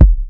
kits/OZ/Kicks/K_Lose.wav at main